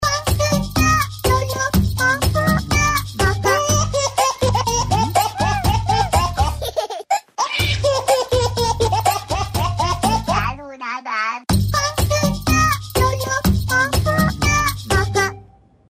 • Качество: 128, Stereo
забавные
веселые
детский голос